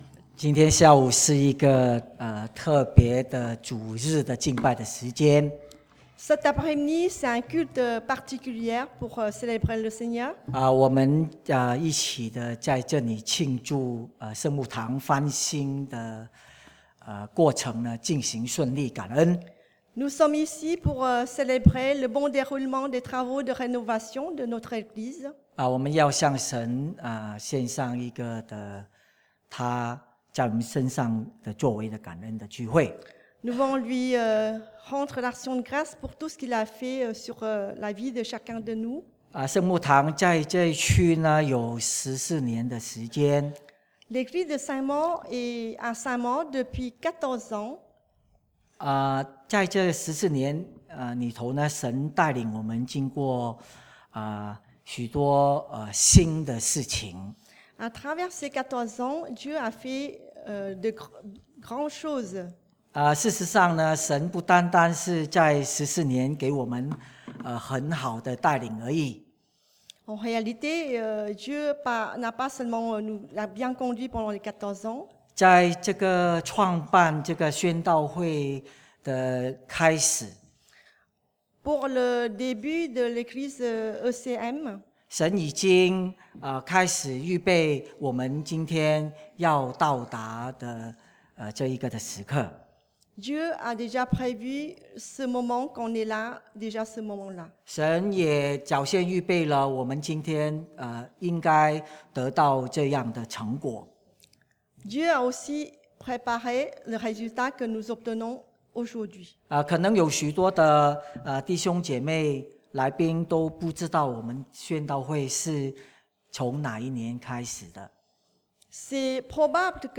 Dieu fait de nouvelles choses – 1 Rois 8：14-20 (Spécial Culte de Reconnaissance suite à la rénovation des locaux)
Culte de remerciement suite à la rénovation de la salle de culte